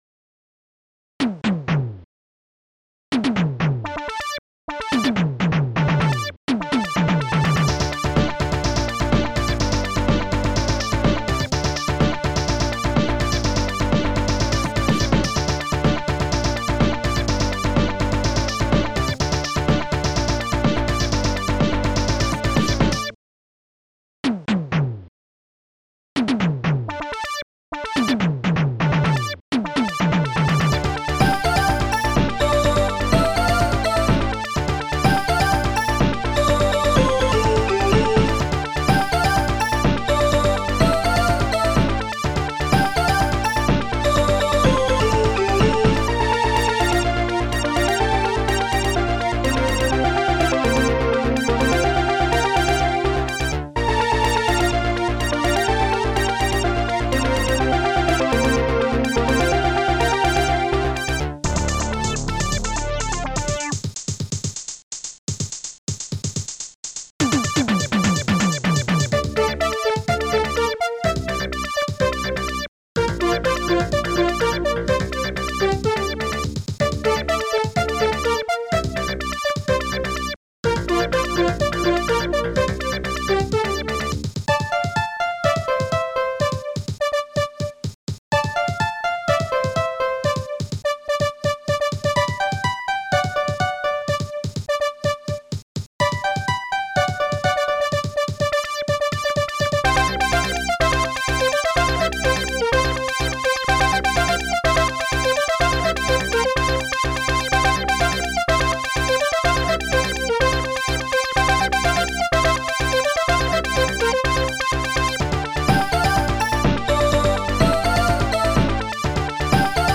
Protracker Module
Instruments bassdrum2 korgfilter electom tunebass strings1 ringpiano hallbrass hihat2 korgdoi snare1 touch steinway squares funkbass closehihat